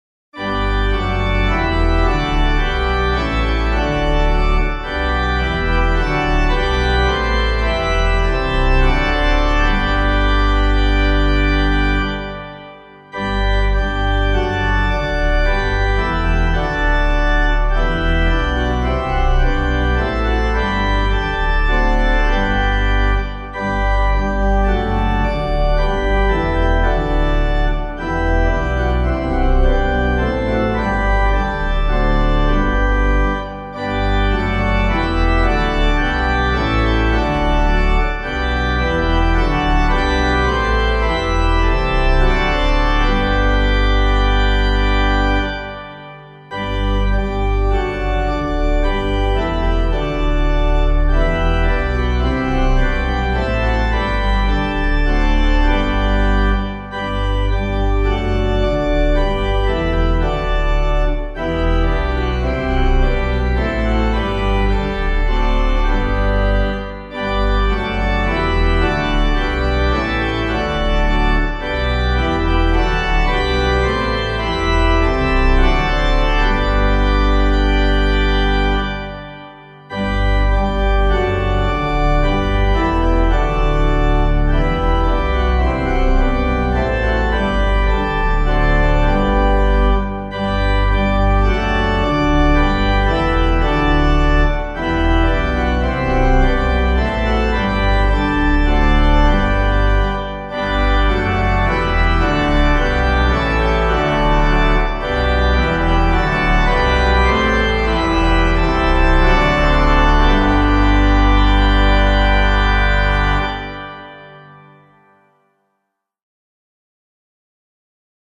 Meter:    78.78.88